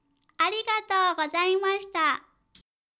ボイス
女性挨拶